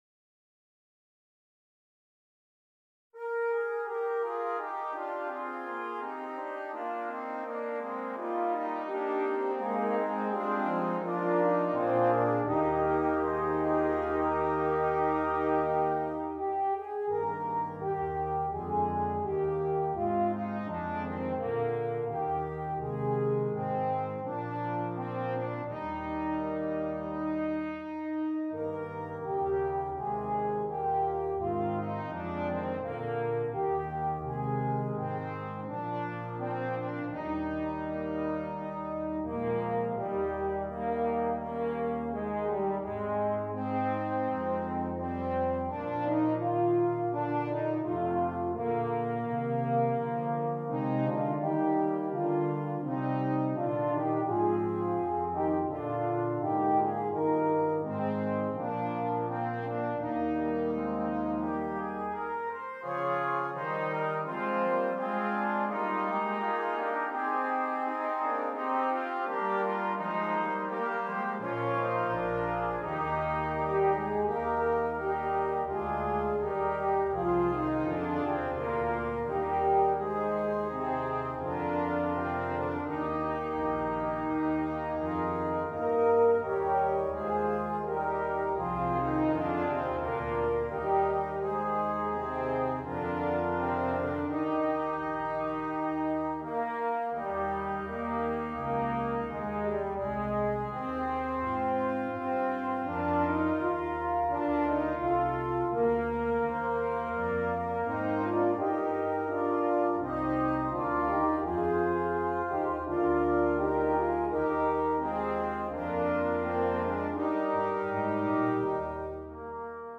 Brass Choir (3.2.2.1.1)
Traditional